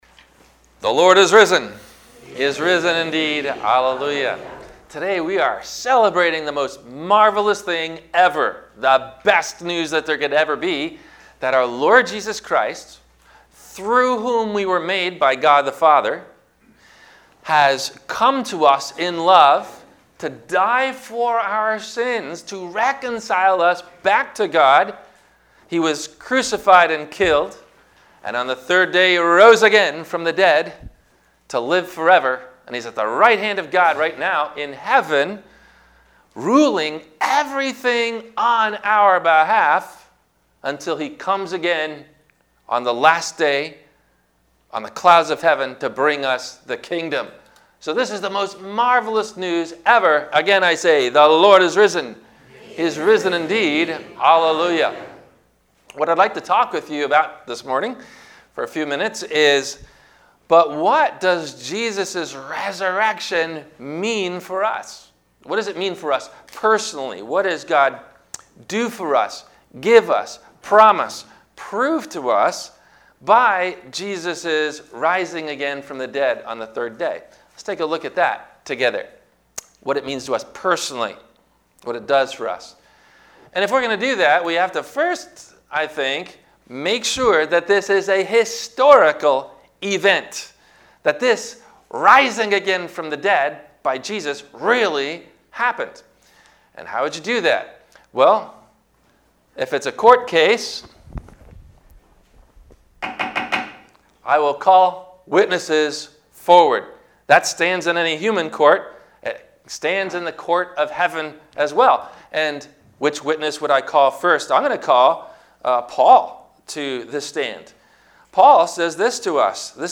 - Easter Resurrection Sunday - Sermon - April 04 2021 - Christ Lutheran Cape Canaveral
NOTE: THE AUDIO ONLY SERMON BUTTON BELOW IS FROM THE 10:00 AM SERVICE AT THE CHURCH, AND NOT FROM THE SUNRISE SERVICE.